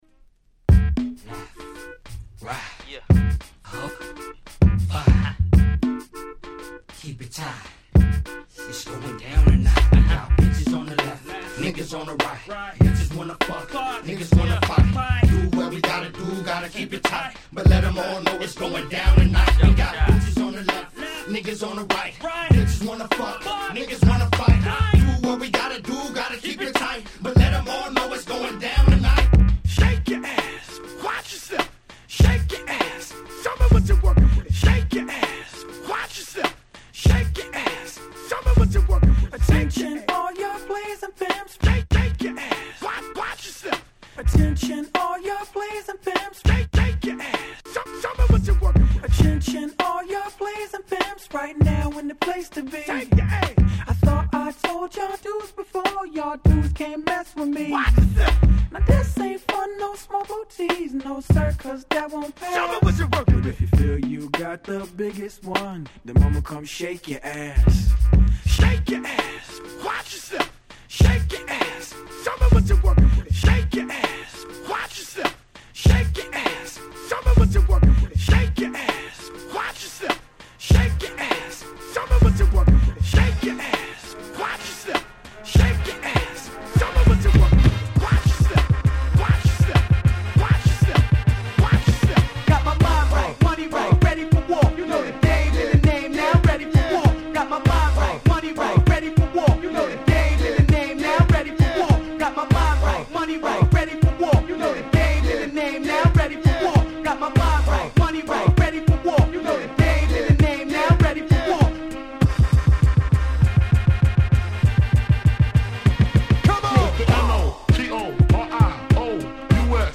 00' Nice Party Tracks !!